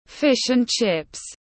Cá rán tẩm bột và khoai tây chiên tiếng anh gọi là fish and chips, phiên âm tiếng anh đọc là /ˌfɪʃ en ˈtʃɪps/
Fish and chips /ˌfɪʃ en ˈtʃɪps/